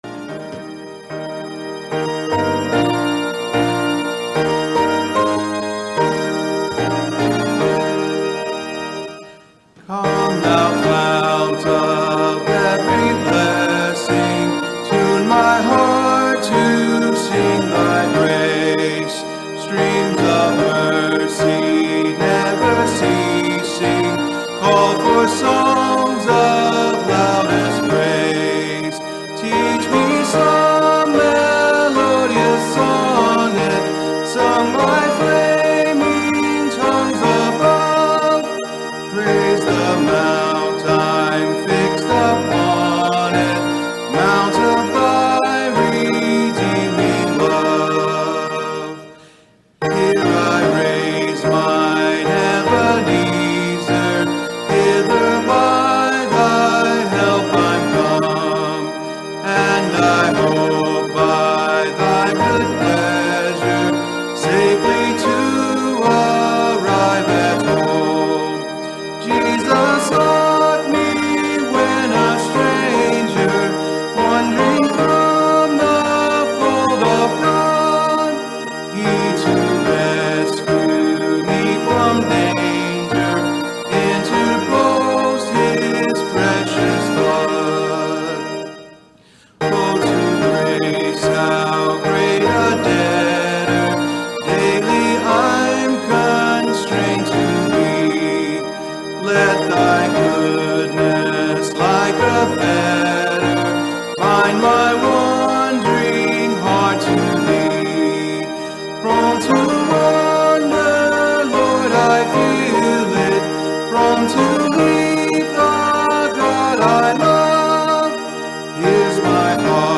The Truth and The Lie | SermonAudio Broadcaster is Live View the Live Stream Share this sermon Disabled by adblocker Copy URL Copied!